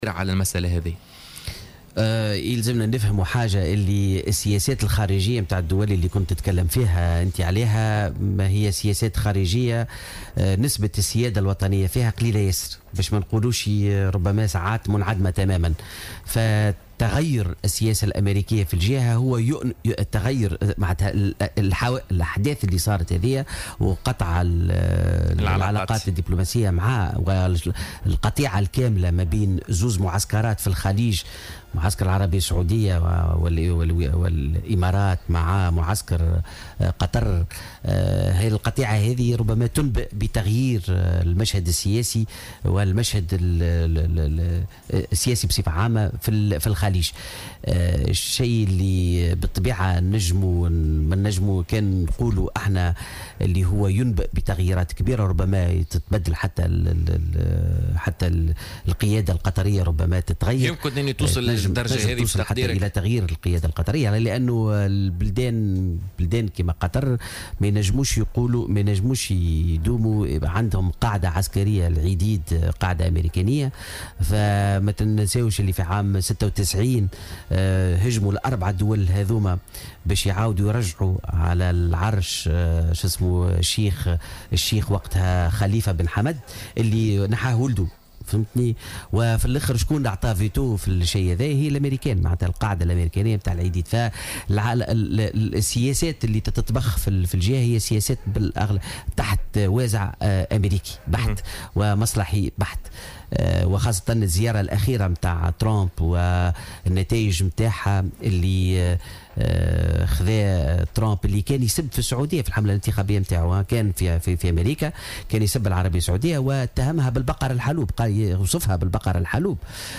ورجّح ضيف "بوليتيكا" على "الجوهرة أف أم"، ان تشهد منطقة الخليج تغييرا في المشهد السياسي مؤكدا أن المصالح الأميركية هي الورقة الحاسمة في هذه المنطقة.